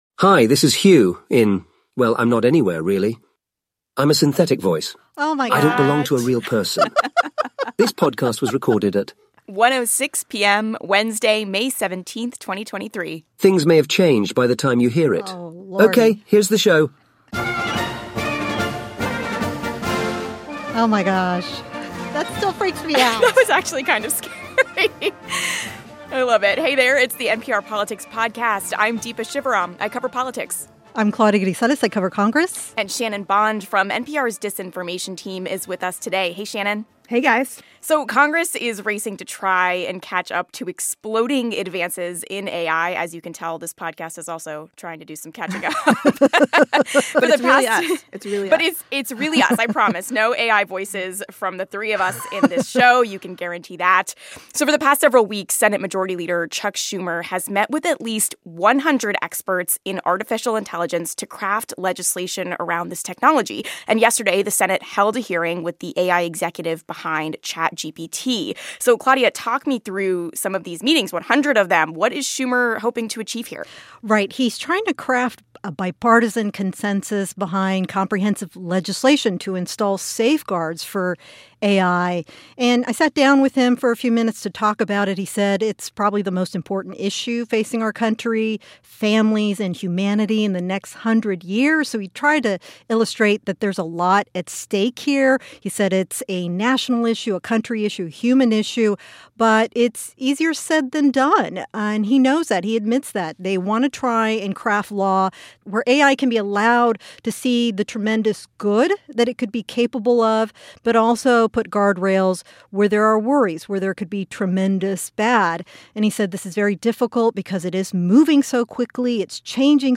This episode: political reporter
disinformation correspondent
congressional correspondent